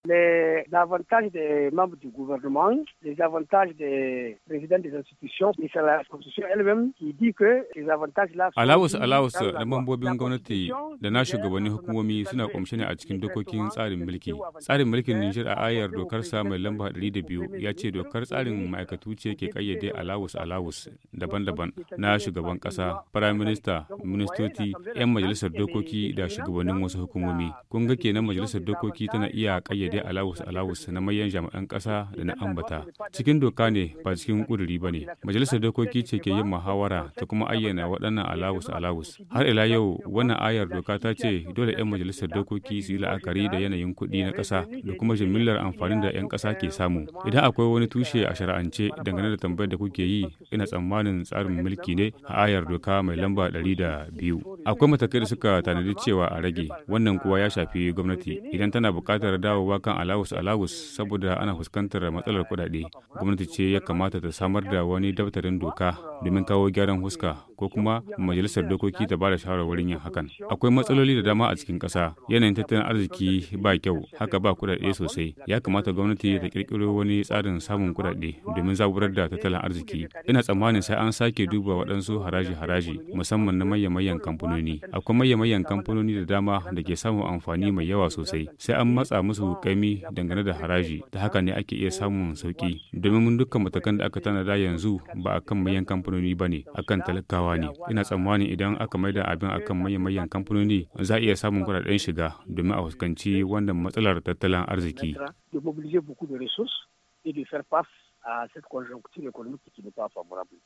Propos reccueillis au Téléphone